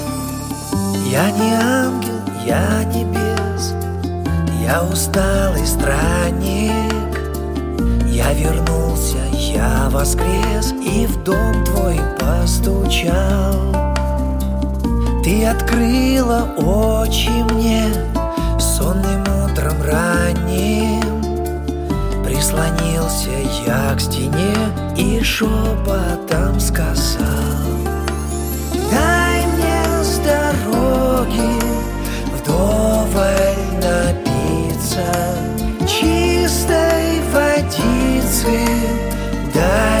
гитара , поп , баллады